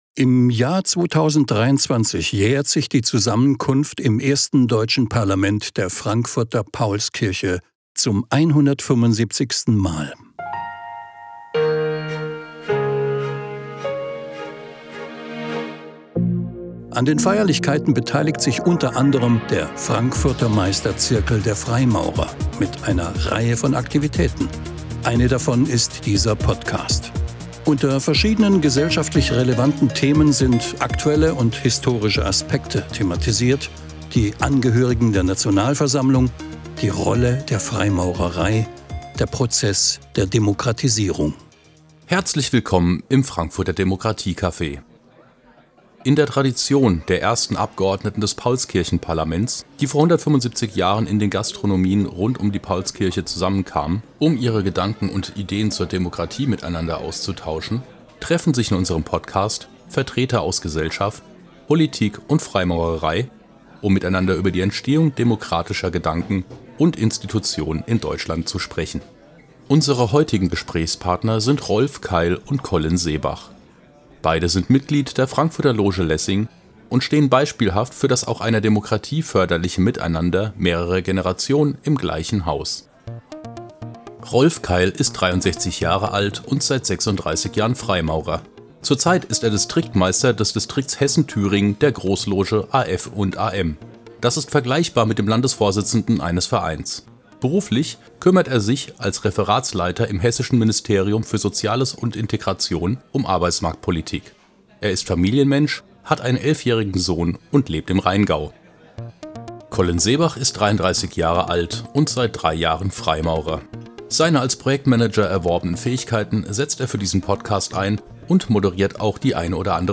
Freimaurer-Interviews Podcast